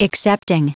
Prononciation du mot excepting en anglais (fichier audio)
Prononciation du mot : excepting